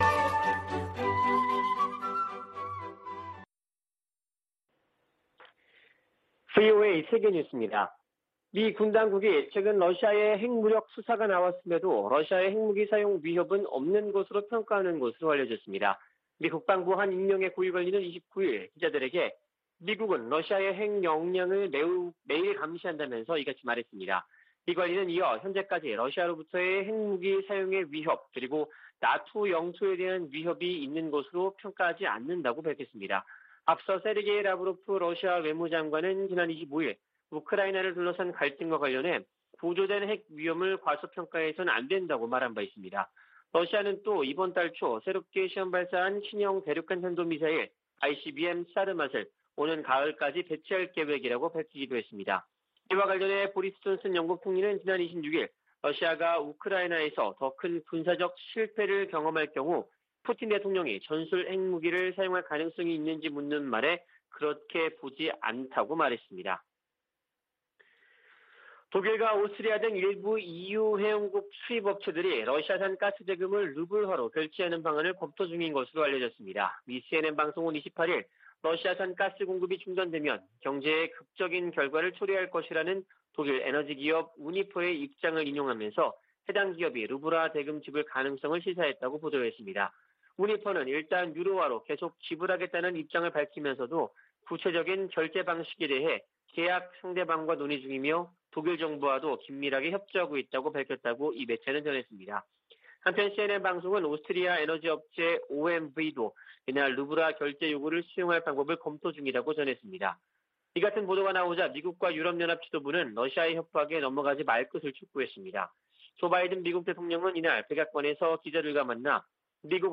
VOA 한국어 아침 뉴스 프로그램 '워싱턴 뉴스 광장' 2022년 4월 30일 방송입니다. 다음 달 미한 정상회담에서는 동맹강화와 대북공조 등이 중점 논의될 것으로 보입니다.